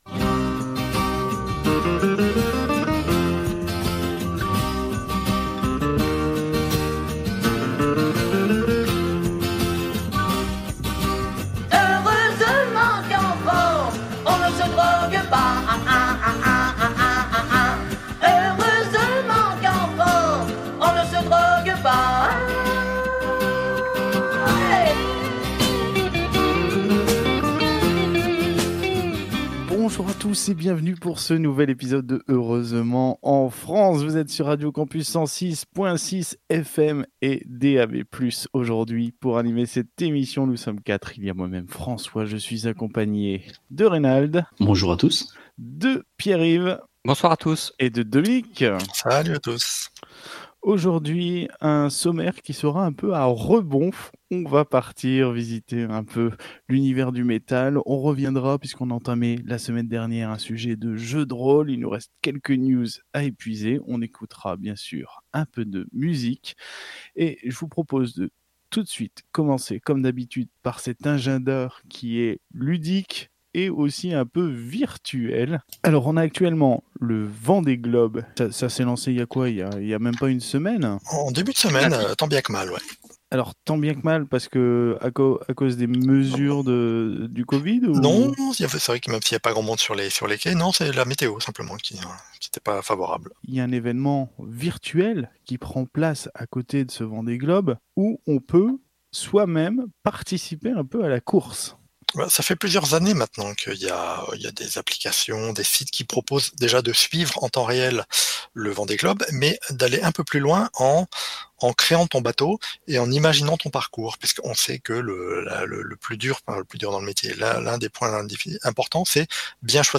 Au sommaire de cet épisode diffusé le 15 novembre 2020 sur Radio Campus 106.6 :